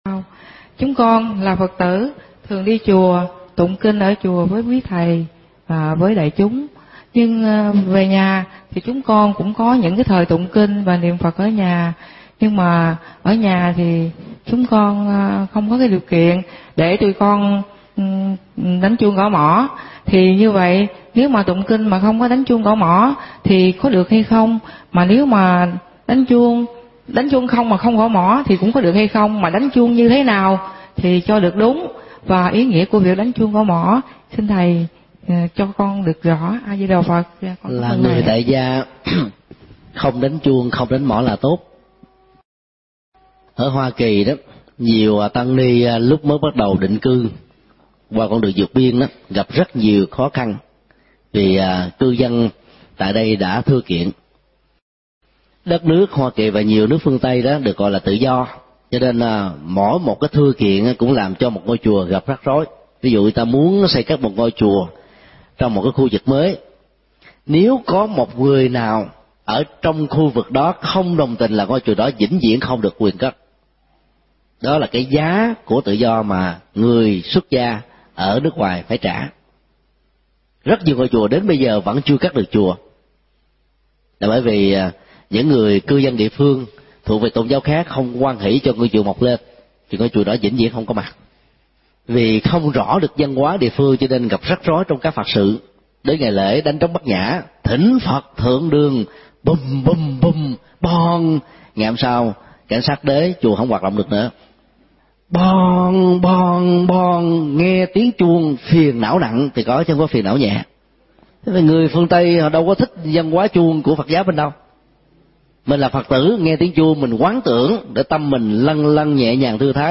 Vấn đáp: Văn hóa tụng-trì kinh